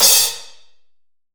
Index of /90_sSampleCDs/AKAI S6000 CD-ROM - Volume 3/Crash_Cymbal2/SHORT_DECAY_CYMBAL